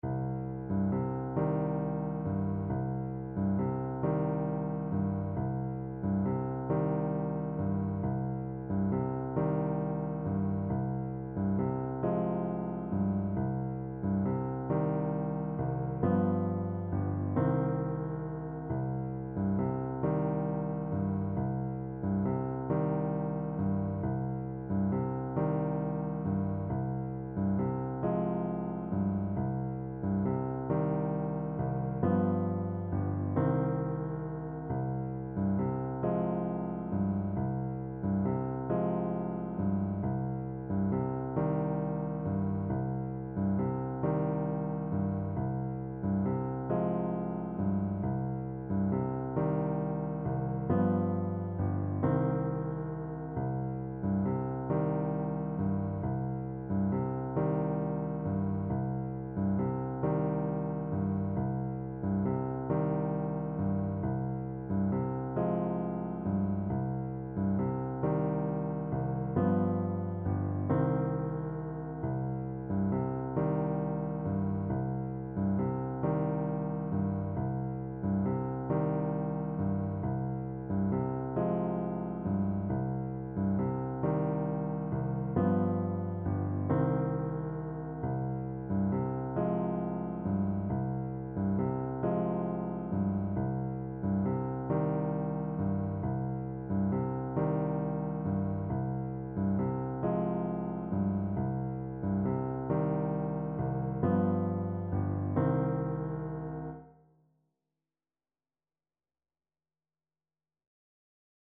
Play (or use space bar on your keyboard) Pause Music Playalong - Piano Accompaniment Playalong Band Accompaniment not yet available reset tempo print settings full screen
6/8 (View more 6/8 Music)
C minor (Sounding Pitch) A minor (Alto Saxophone in Eb) (View more C minor Music for Saxophone )
Gently rocking .=c.45